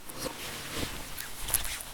trousers_02.ogg